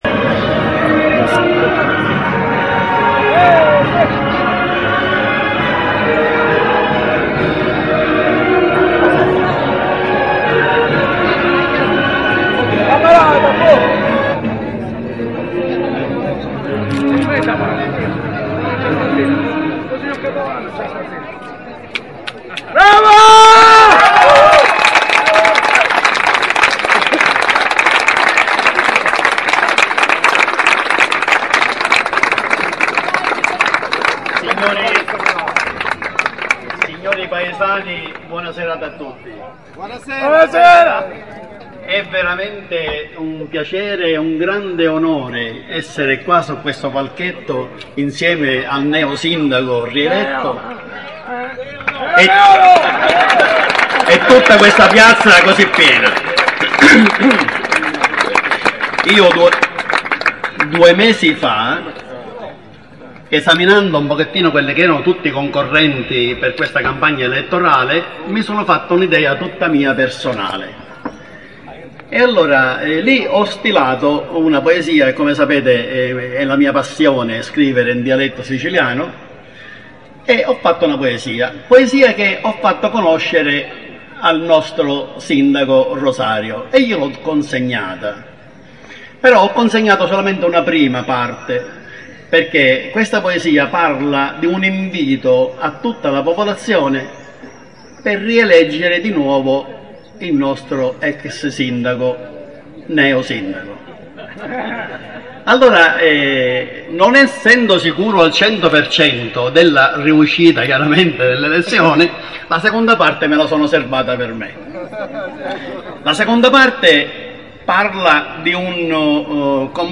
Il Sindaco Lapunzina ringrazia gli elettori. Comizio